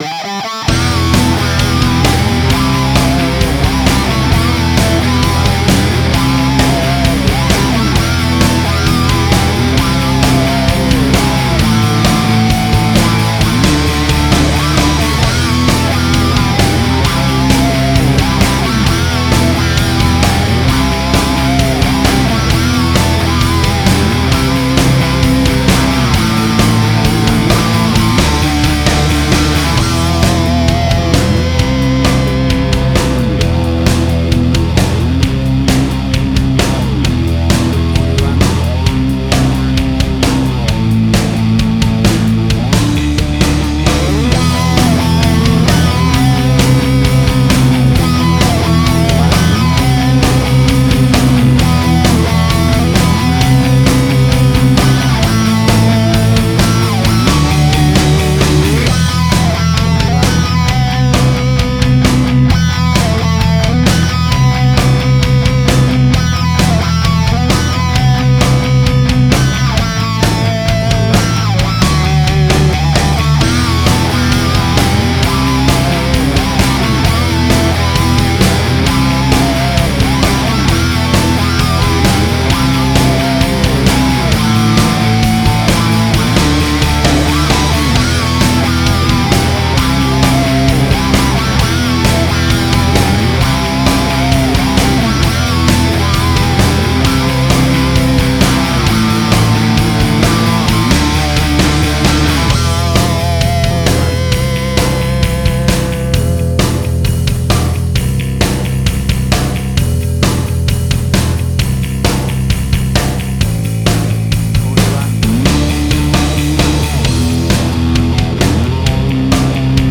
Hard Rock
Heavy Metal.
Tempo (BPM): 132